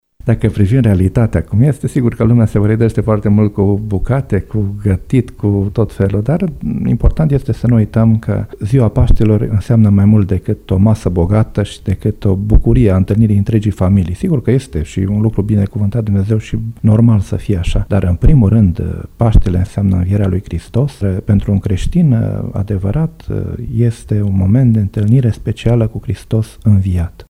preotul greco-catolic